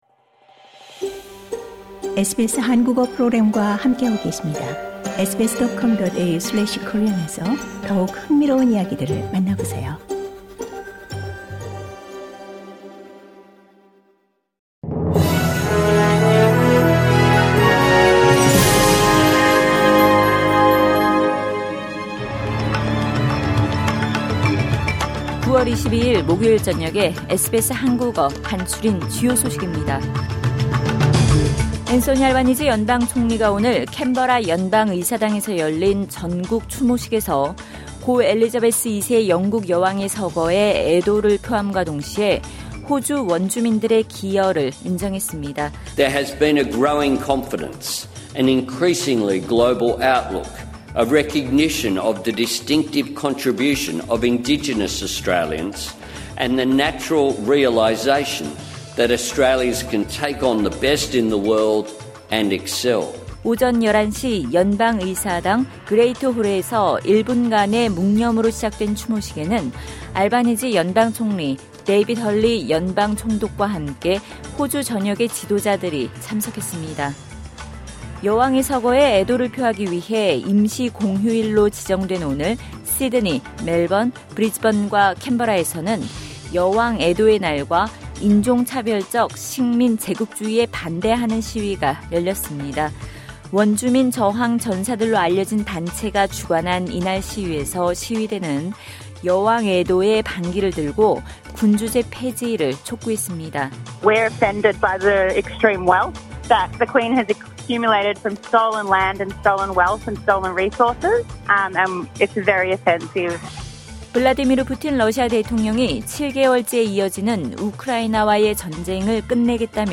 SBS 한국어 저녁 뉴스: 2022년 9월 22일 목요일